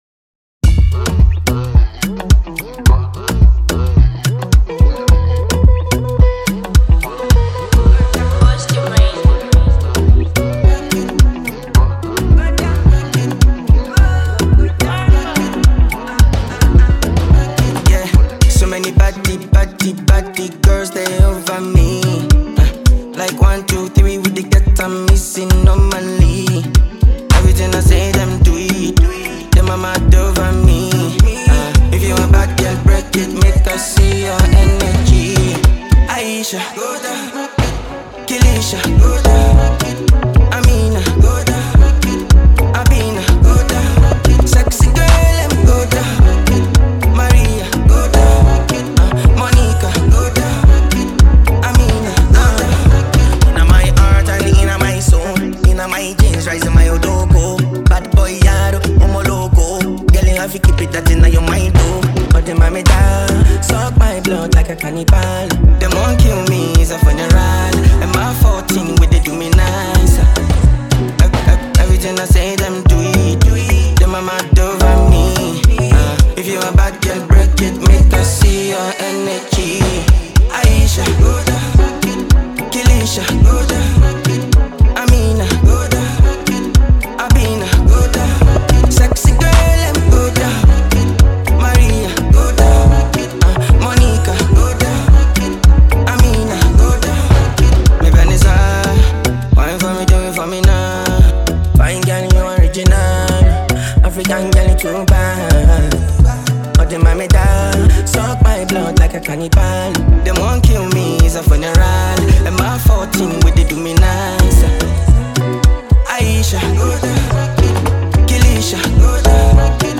Ghanaian singer and afrobeat musician